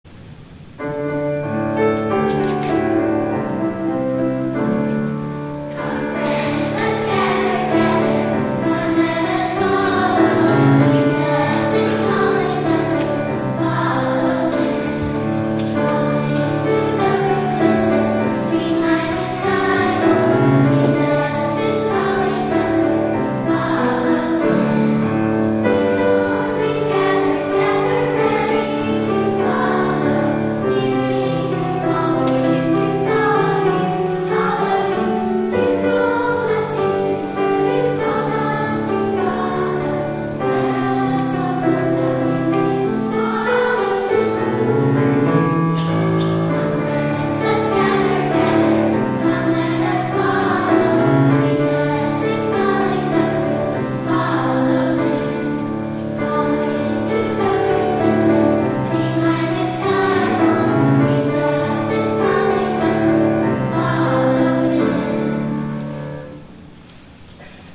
Jesus Is Calling (Combined Choirs)  James Ritchie